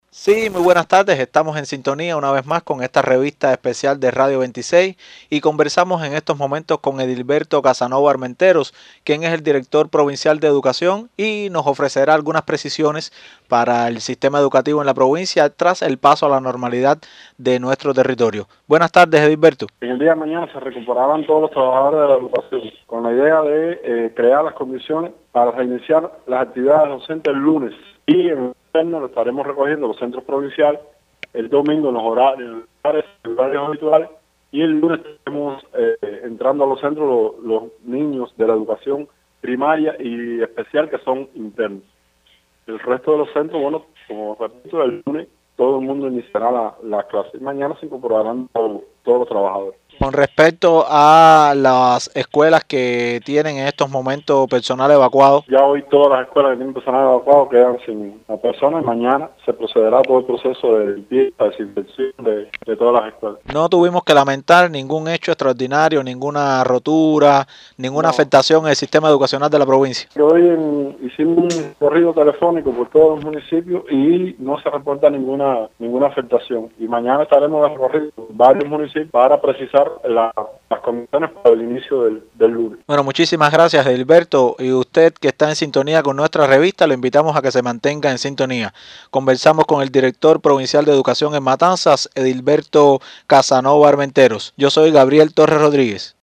«Mañana se realizarán visitas detalladas a varios municipios para verificar las condiciones exactas para el reinicio de actividades», abundó Casanova Armenteros en sus declaraciones, mientras subrayó la importancia de estos recorridos, que permitirán asegurar que todas las instalaciones escolares están completamente preparadas para recibir de nuevo a los estudiantes.